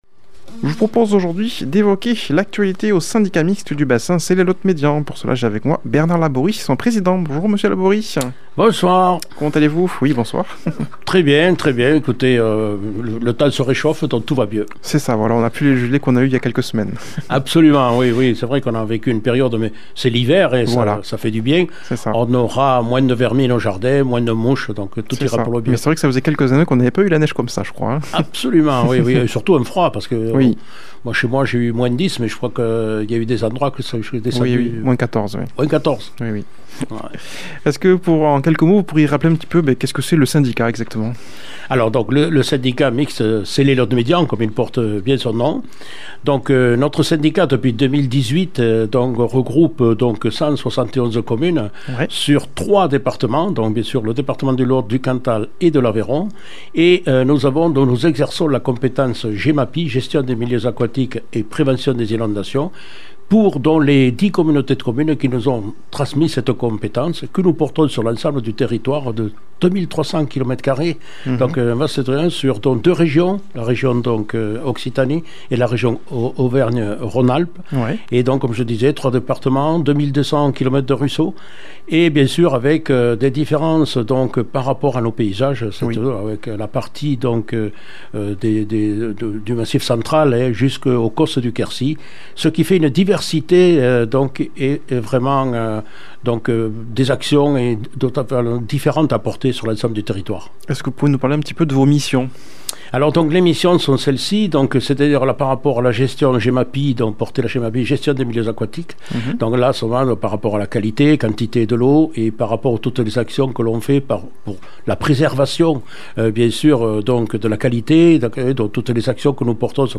a comme invité au studio